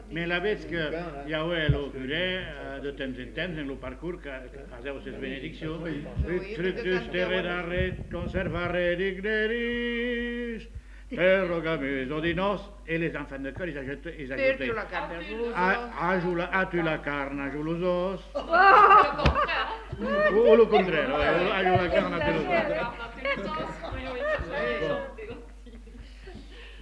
Aire culturelle : Bazadais
Lieu : Uzeste
Genre : conte-légende-récit
Effectif : 1
Type de voix : voix d'homme
Production du son : chanté
Classification : parodie du sacré